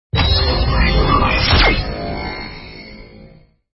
主角获取生命道具音效.mp3